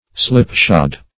Slipshod \Slip"shod`\, a.